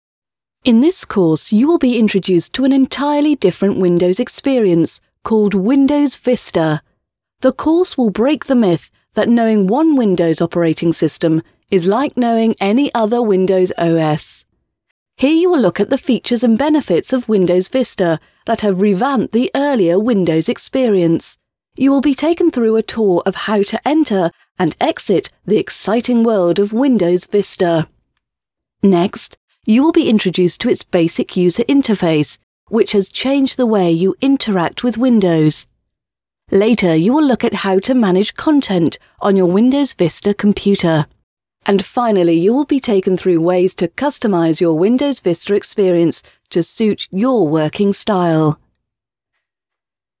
englische Profi-Sprecherin mit britischem Akzent.
Sprechprobe: Industrie (Muttersprache):
Female Voiceover Artist with native British Accent.